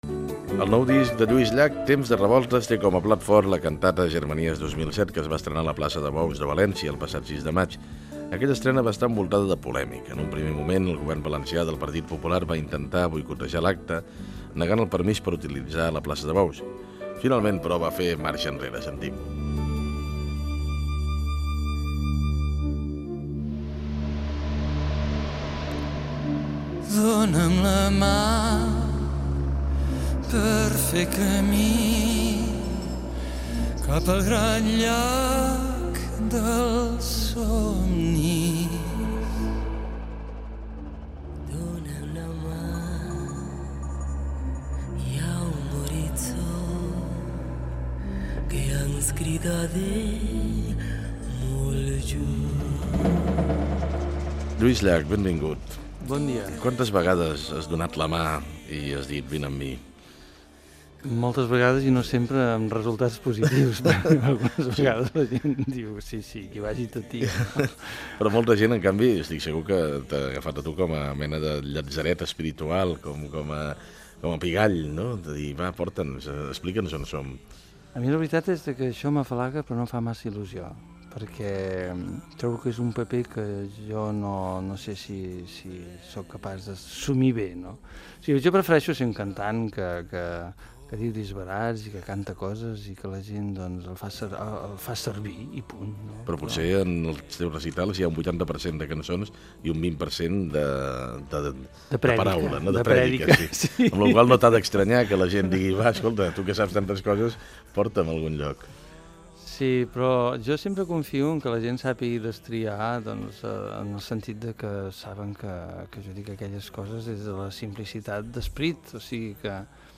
Fragment d'una entrevista al cantautor Lluís Llach i perfil sobre el convidat
Info-entreteniment